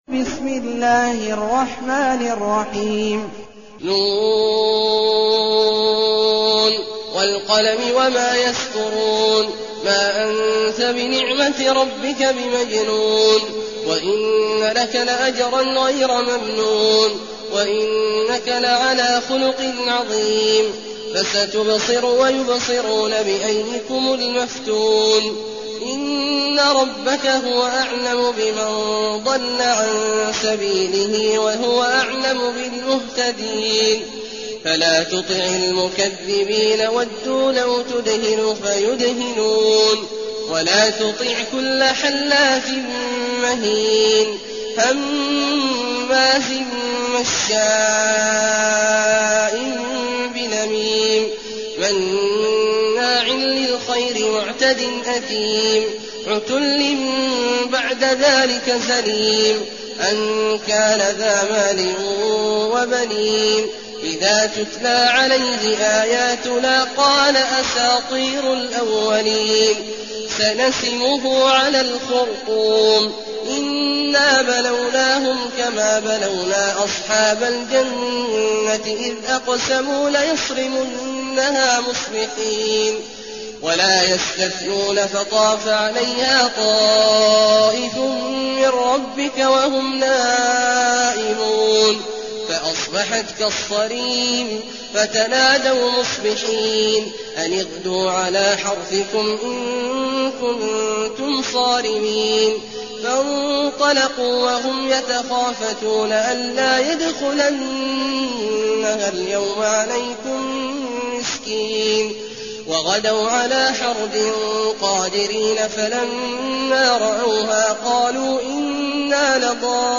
المكان: المسجد النبوي الشيخ: فضيلة الشيخ عبدالله الجهني فضيلة الشيخ عبدالله الجهني القلم